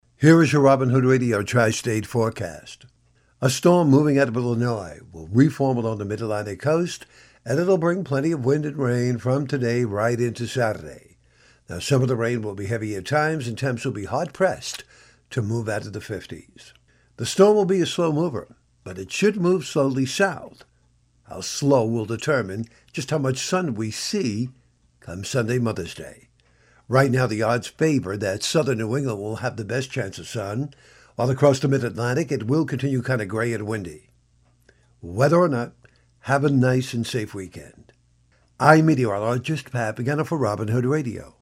Your Robin Hood Radio Tri-State Forecast